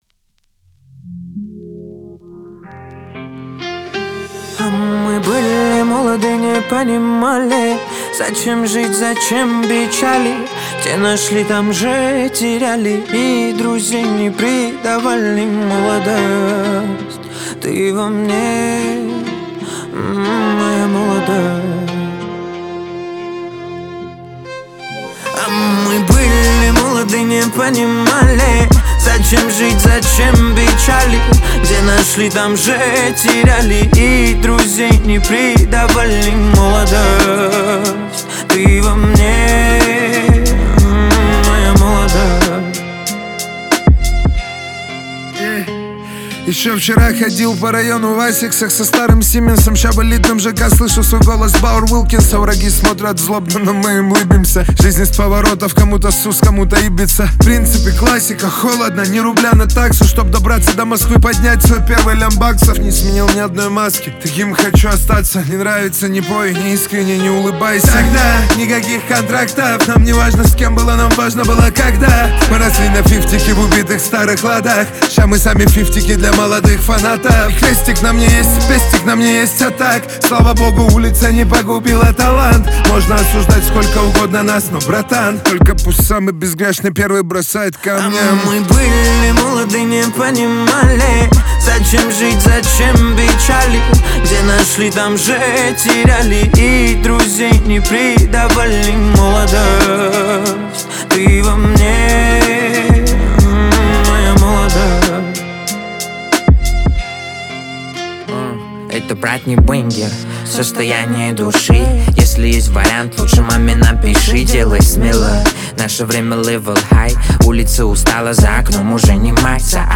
Рейв